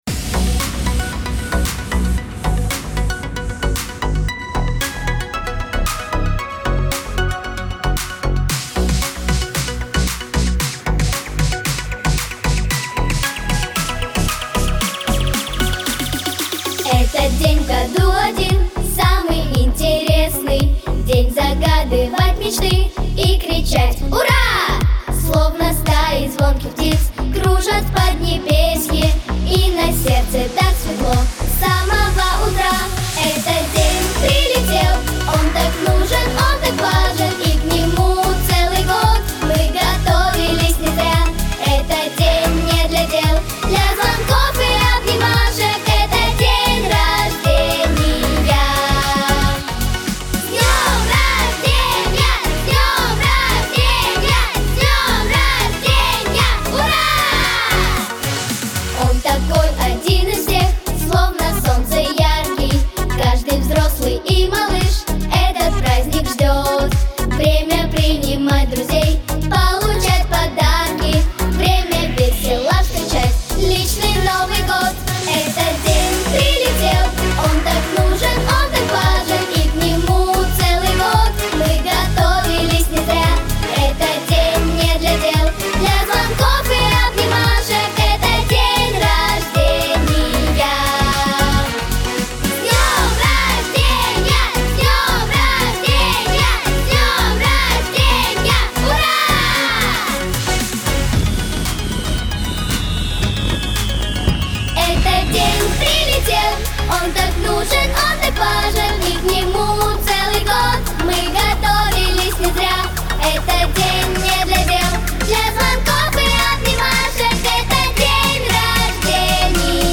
Характер песни: весёлый.
Темп песни: быстрый.